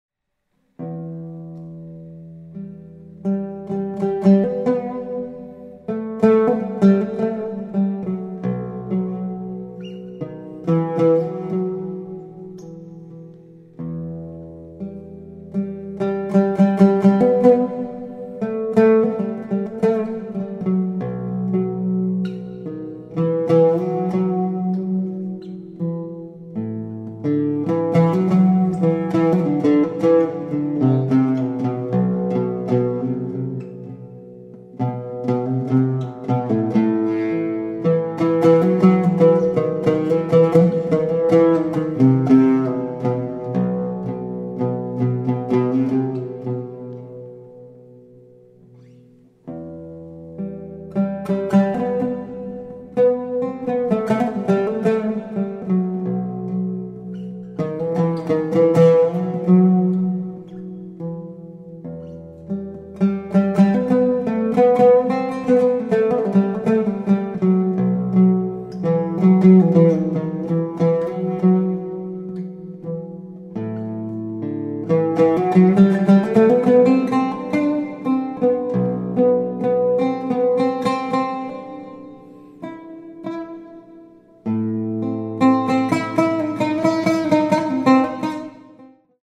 lute & oud player from Japan
Contemporary
Oud